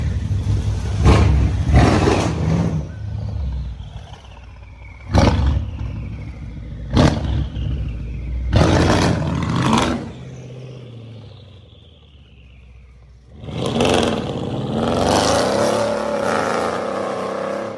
Crazy Straight Pipe From A Sound Effects Free Download